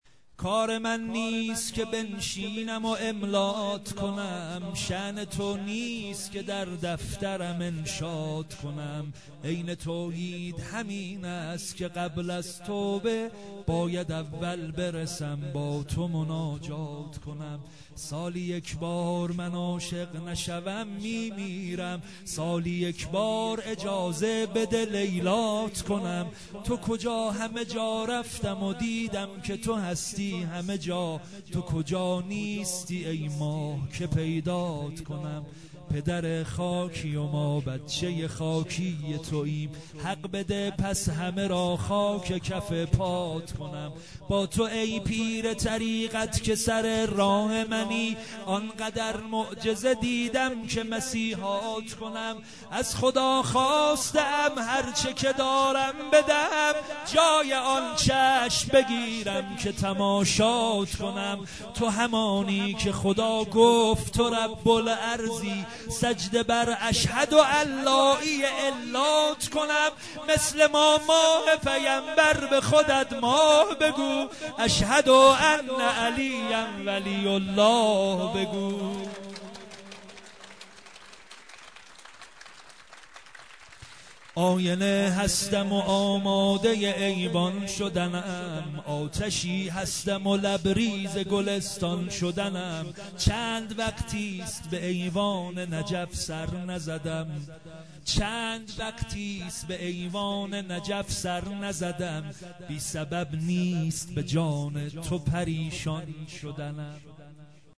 مولودی, غدیر خم